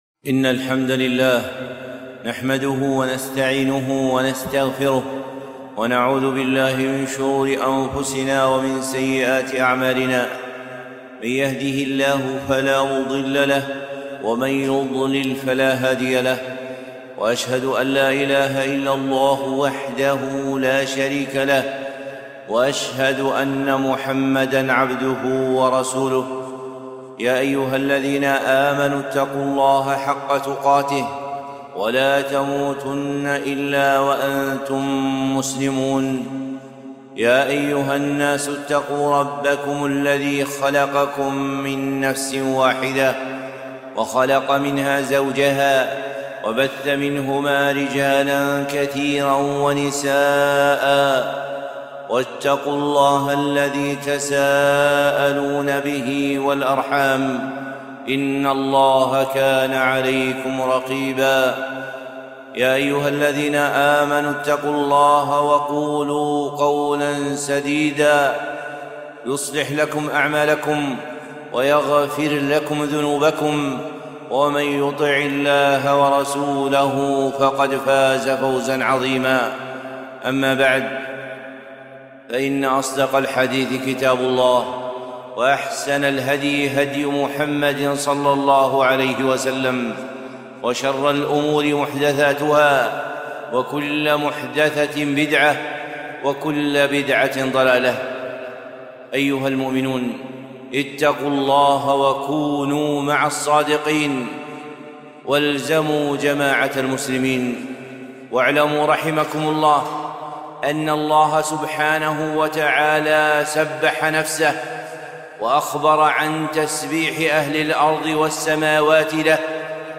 خطبة - أعظم التسبيح